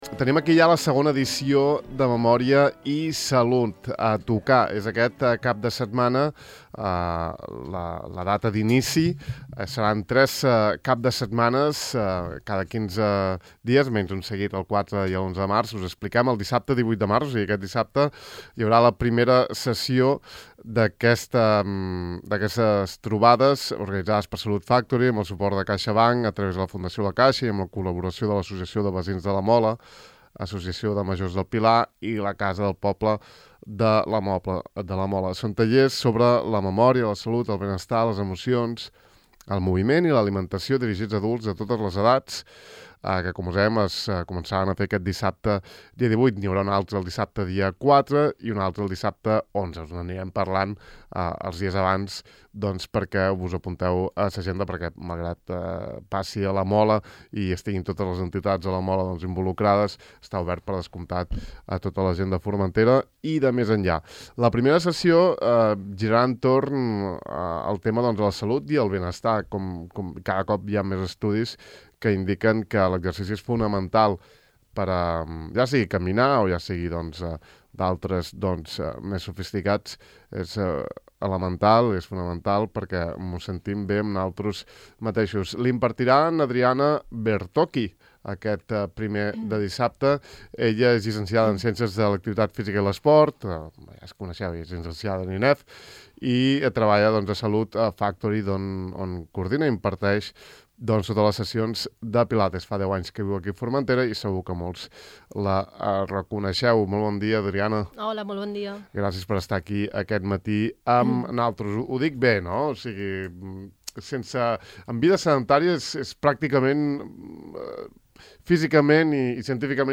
En l’entrevista ens ha parlat bastament i molta solvència de la importància del moviment i la respiració en la salut i el benestar de les persones: